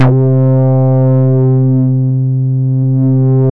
Index of /90_sSampleCDs/Trance_Explosion_Vol1/Instrument Multi-samples/Wasp Bass 2
C3_WaspBass2.wav